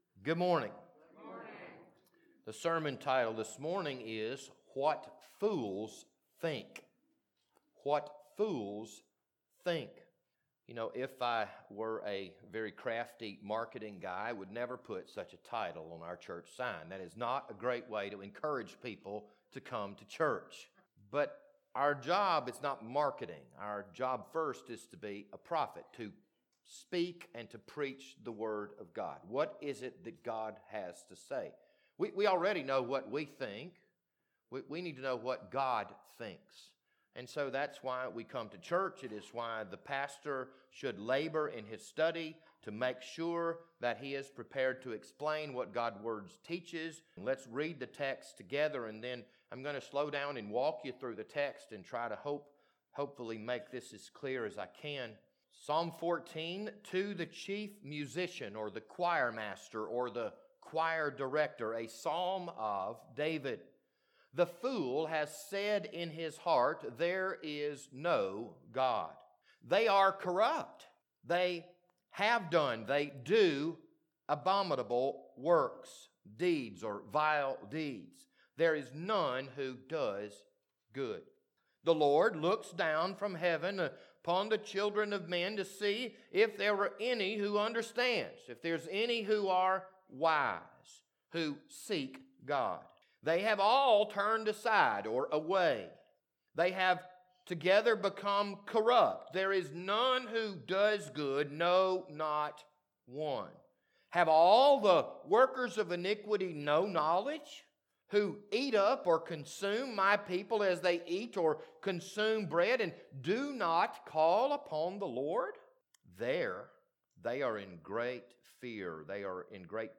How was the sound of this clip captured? This Sunday morning sermon was recorded on March 6th, 2022.